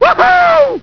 Homer yells,"Woo hoo!"